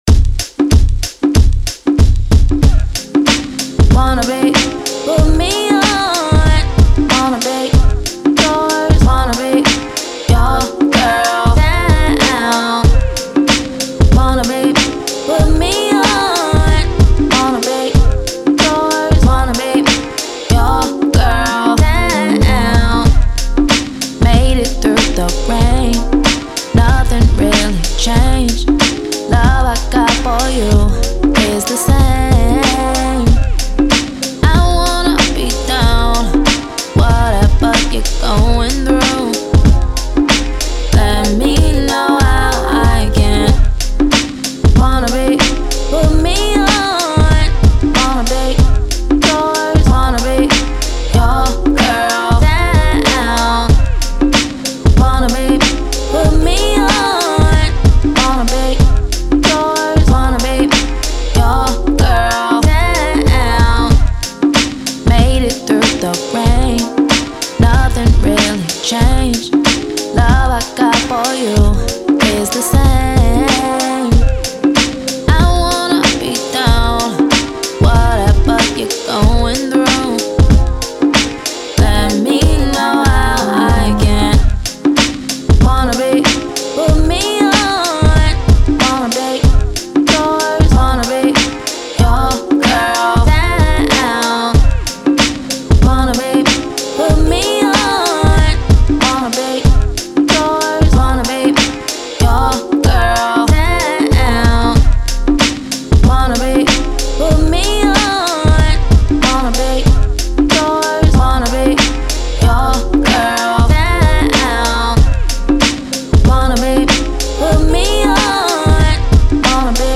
90s, R&B
C Minor